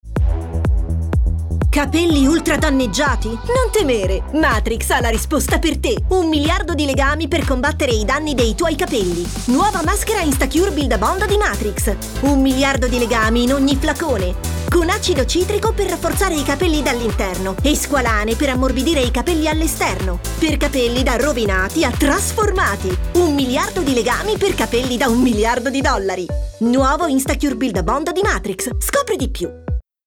FRIZZANTE Matrix Instacure
Spot Beauty
-Locale chiuso fornito di pannelli fonoassorbenti